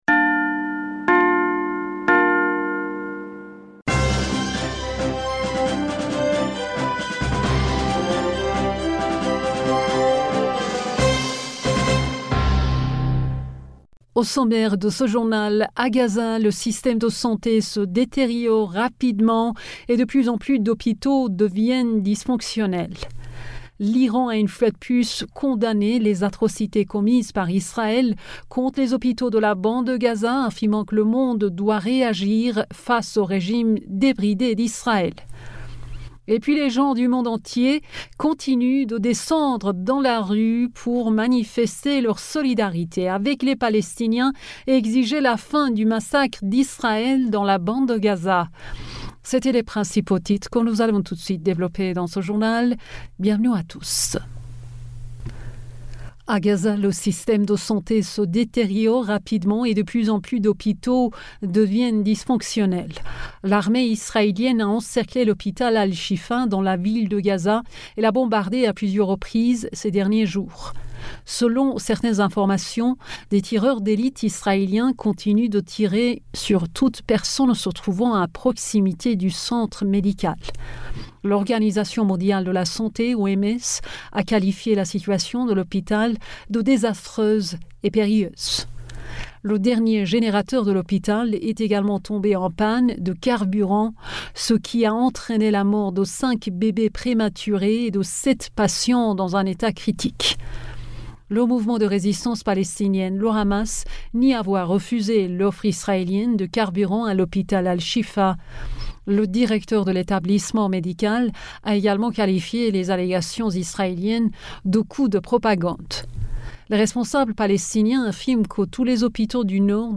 Bulletin d'information du 13 Novembre 2023